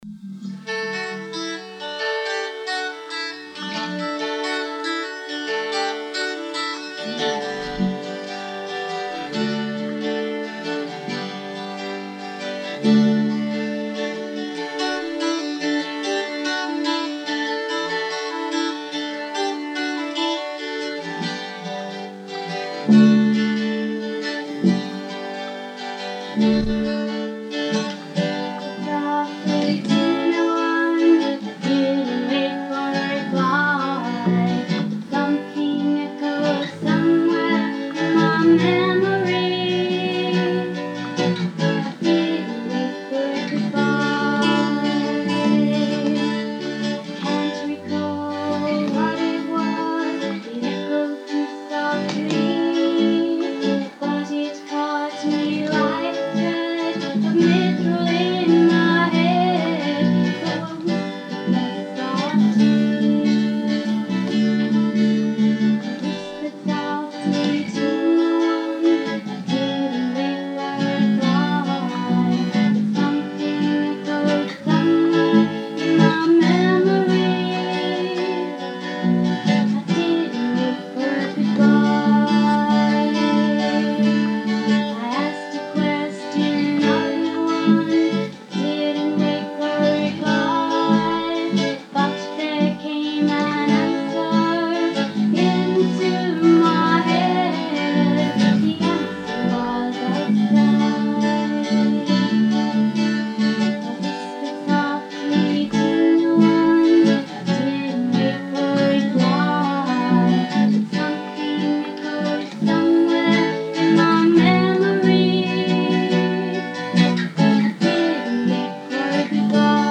The attached file comes from a stereo cassette recording in 1976. Two inbuilt auto limited ECMs in a portable Panasonic cassette payer. 12 string Ibanez Hummingbird
Coronet short scale bass played through a 10w 10" guitar amp